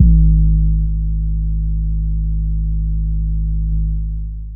Overused 808.wav